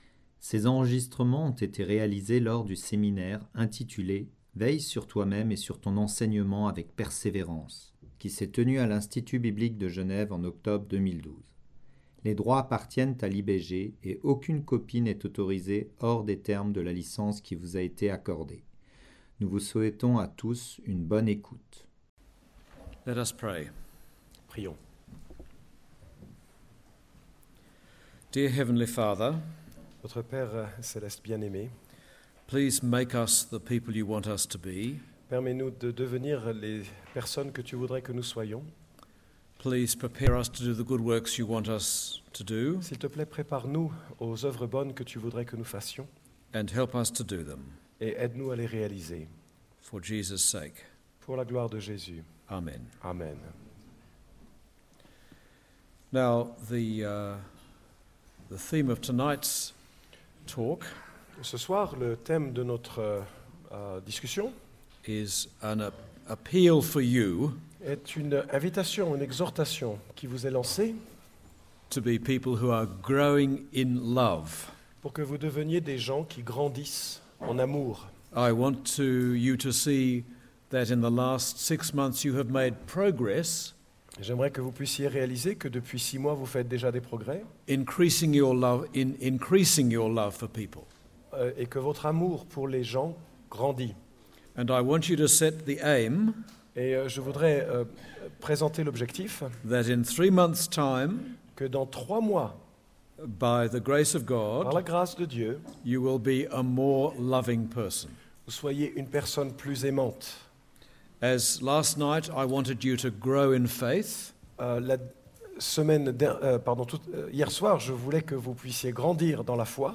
Série: Evangile 21 octobre 2012